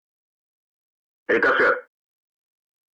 Звук рикошета из World of Tanks